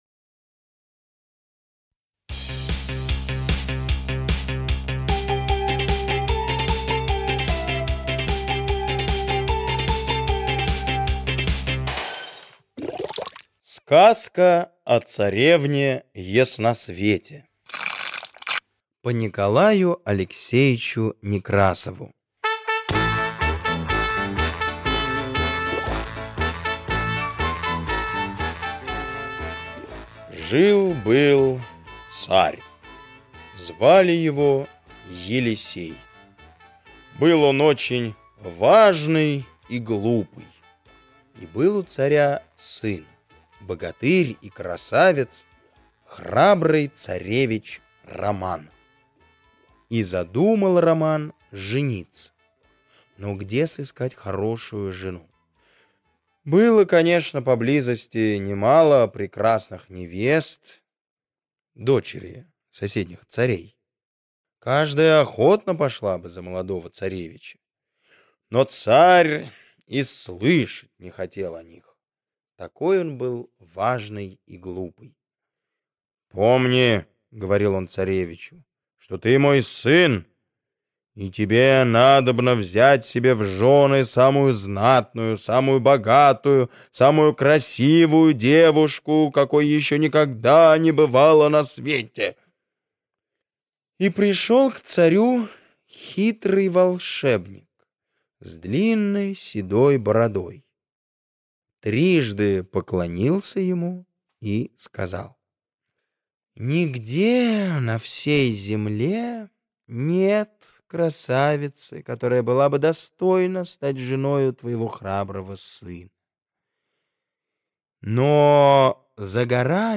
Сказка о царевне Ясносвете - аудиосказка Корнея Чуковского - слушать онлайн